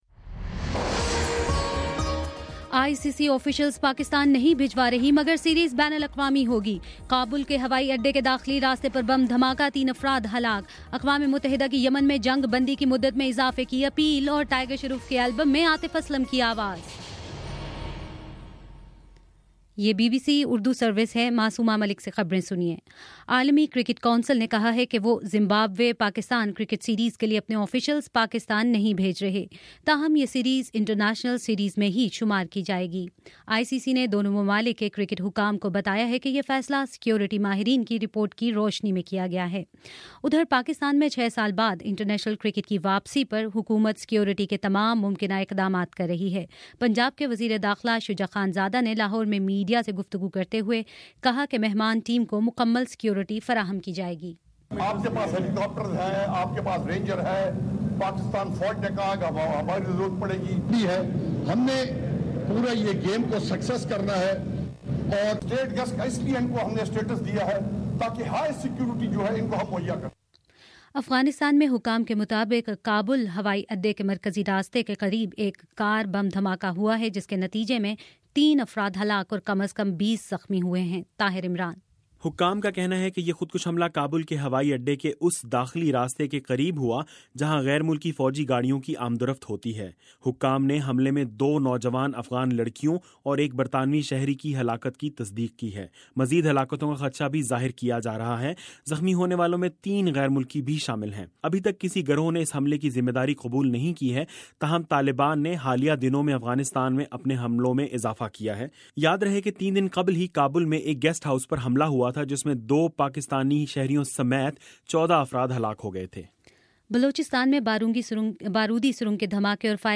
مئی 17: شام چھ بجے کا نیوز بُلیٹن